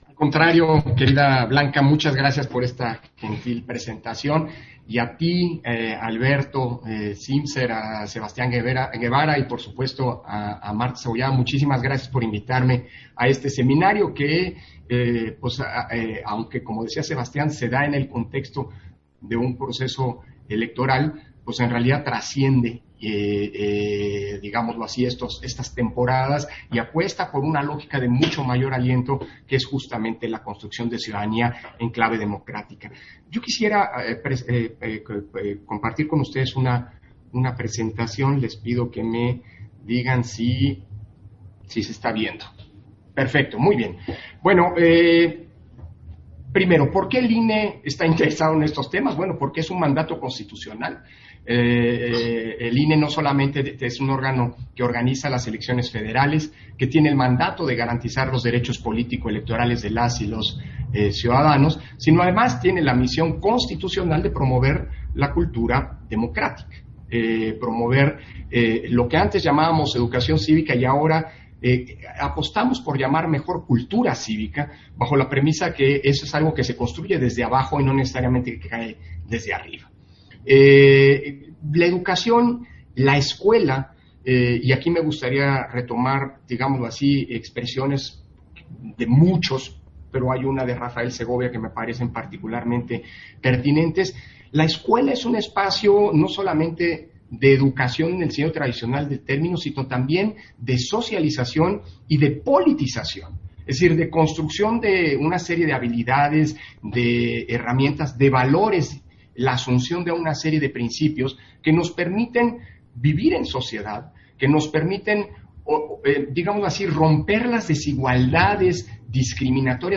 090421_AUDIO_INTERVENCIÓN-CONSEJERO-PDTE.-CÓRDOVA-SEMINARIO-SOBRE-POLÍTICA-EDUCATIVA
Versión estenográfica de la conferencia de Lorenzo Córdova, en el Seminario sobre Política Educativa en México